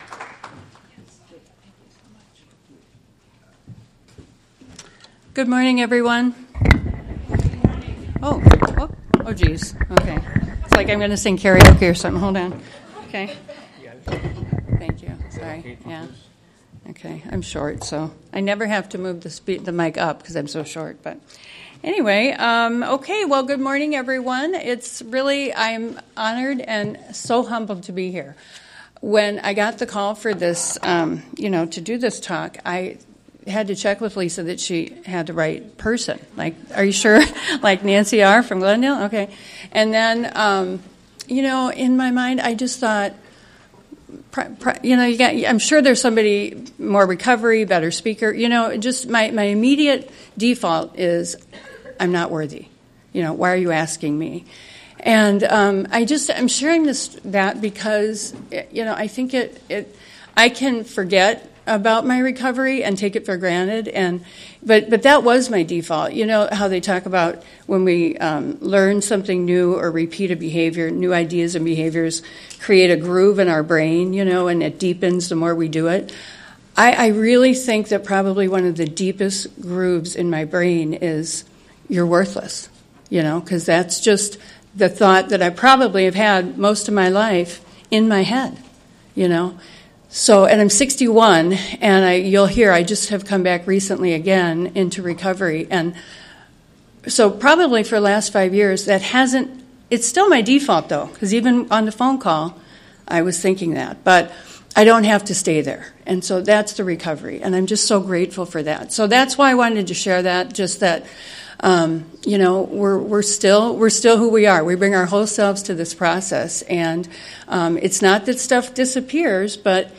2024 OA Milwaukee Area Convention / “Extending Hand and Heart”…